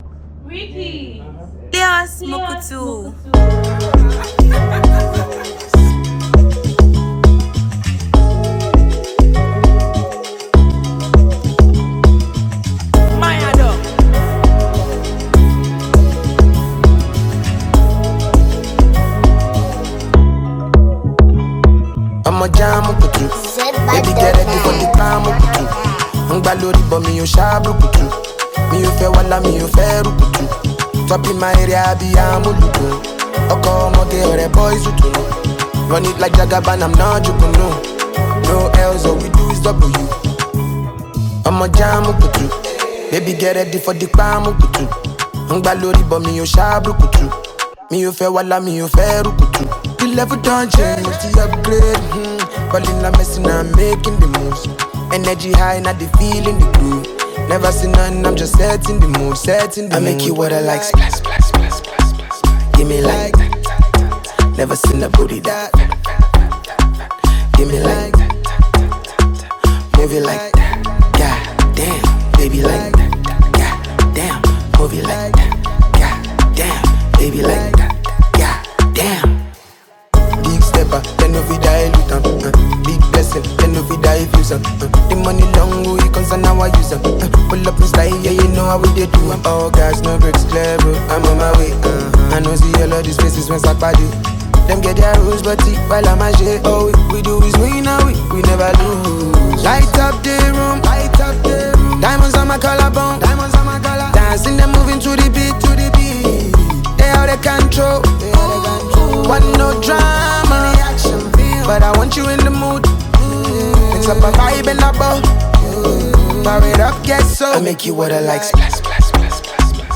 Genre: Afrobeats
Category: Nigerian Music